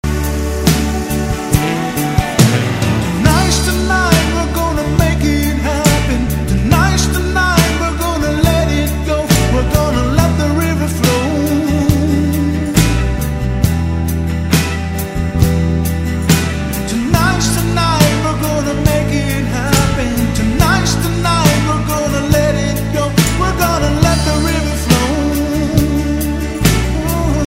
2007's Top Native Gospel Album